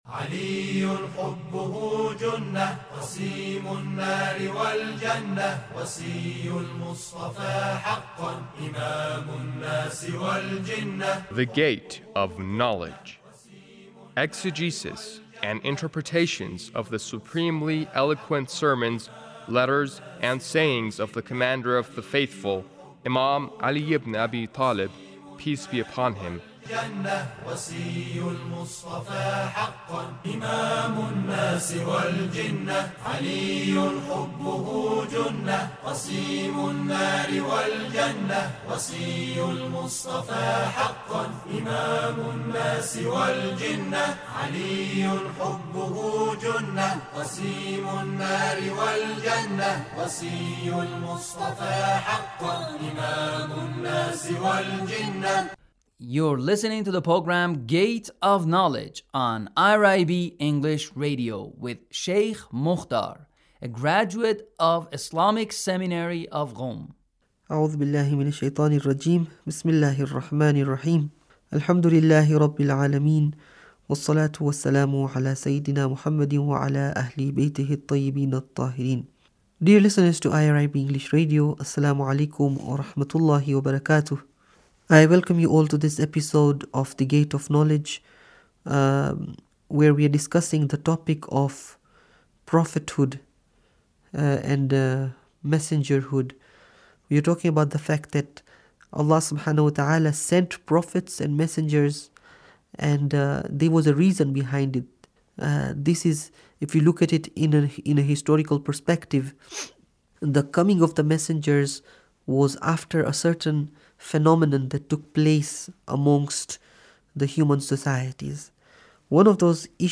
Sermon 1 -